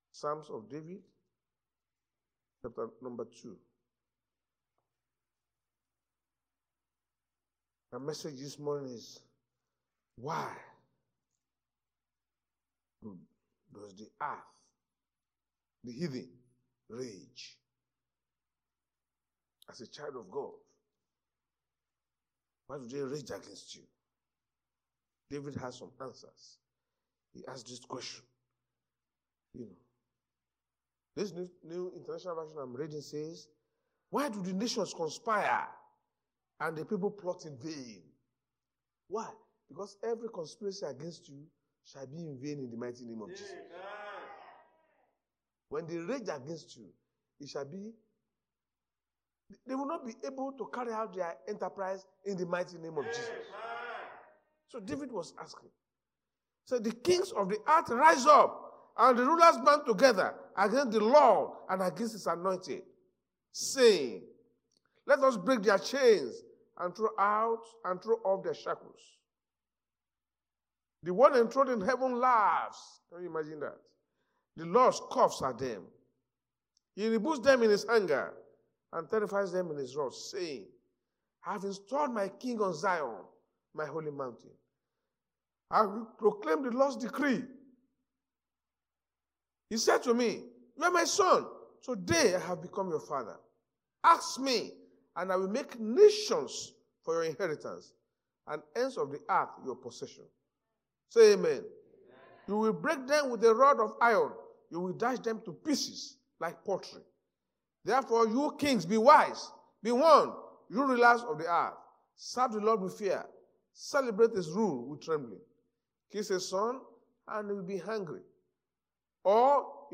Sunday Sermon- Why do the heathens rage?
Service Type: Sunday Church Service